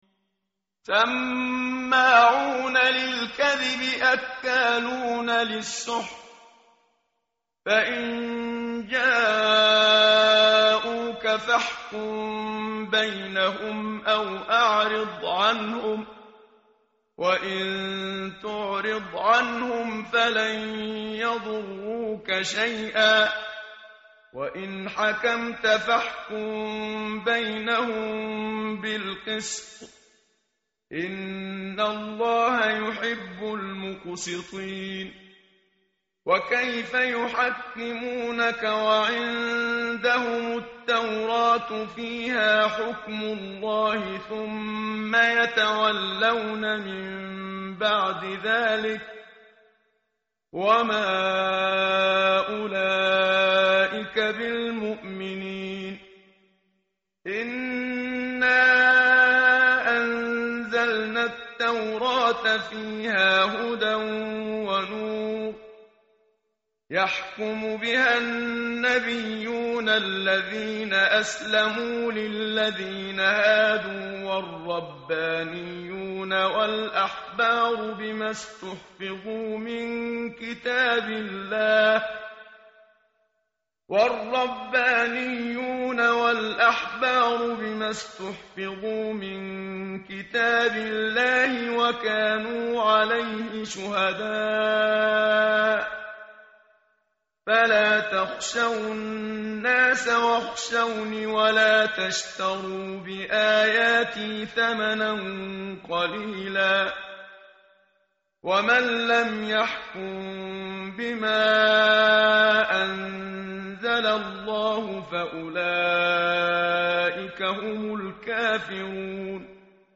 متن قرآن همراه باتلاوت قرآن و ترجمه
tartil_menshavi_page_115.mp3